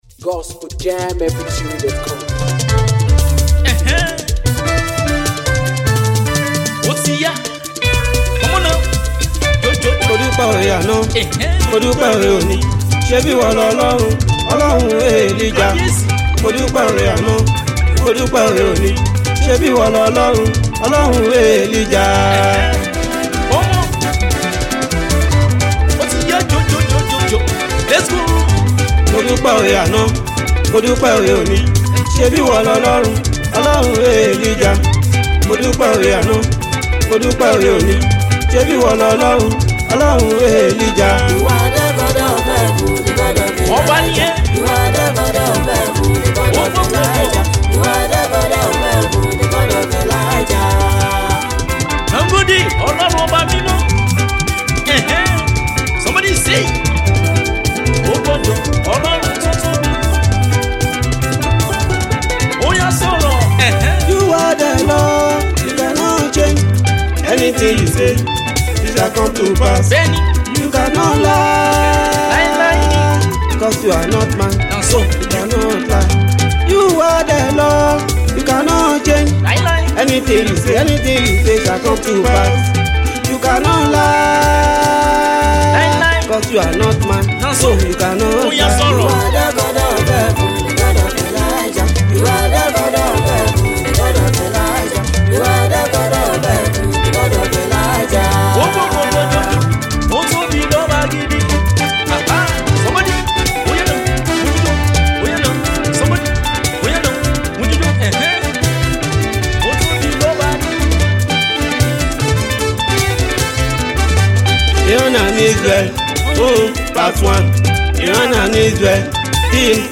sweet beat
the song is fill with Song instrument